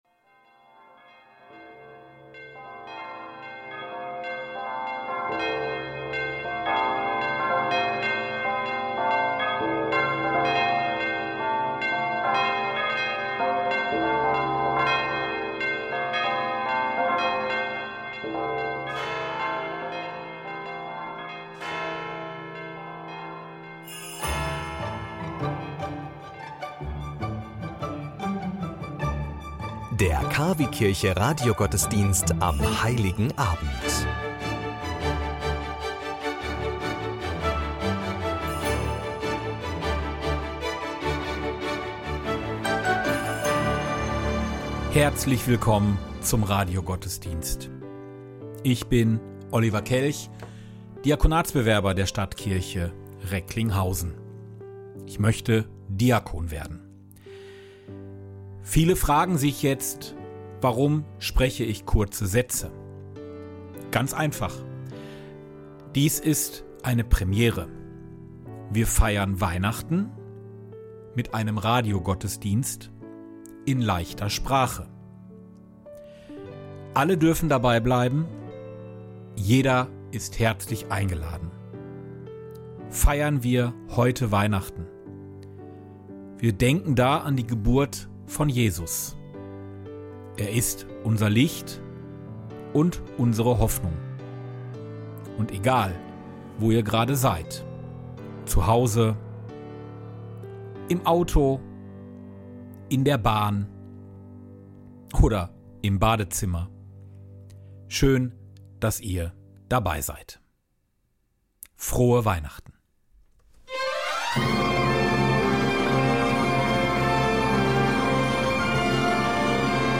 Dieses Jahr feiern wir mit euch den ersten Radiogottesdienst in leichter Sprache.